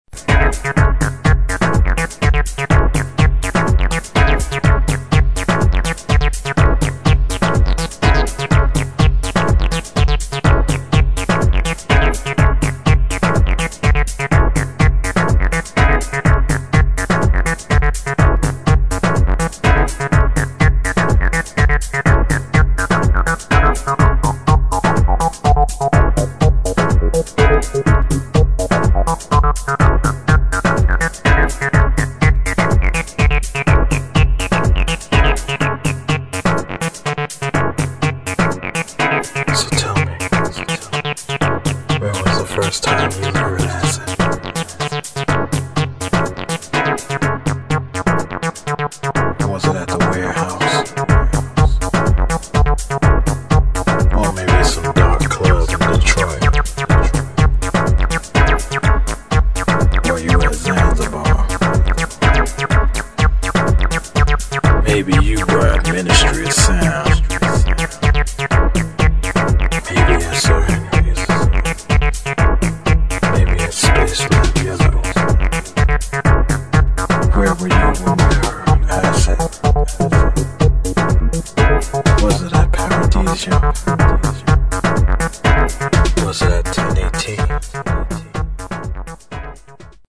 HOUSE | ACID